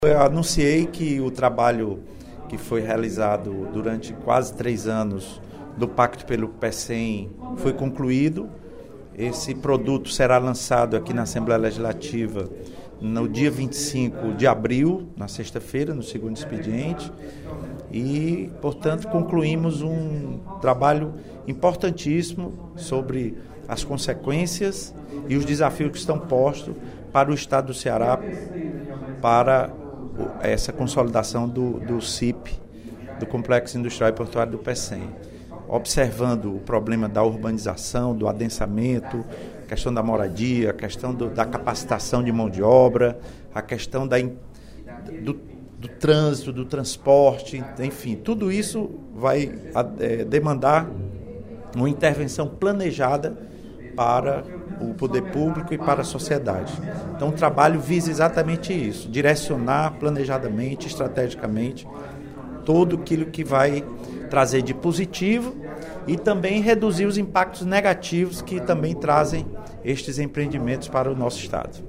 No primeiro expediente da sessão plenária desta sexta-feira (11/04), o deputado Lula Morais (PCdoB) anunciou que será lançado, no dia 25 de abril, o documento com a conclusão dos estudos e debates sobre potencialidades e consequências da instalação do Complexo Industrial e Portuário do Pecém.
Em aparte, o deputado Roberto Mesquita (PV) parabenizou o conselho pela conclusão do estudo e disse que, para ele, é o resultado de um trabalho feito por homens que têm visão de futuro.